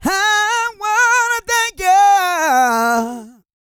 E-GOSPEL 227.wav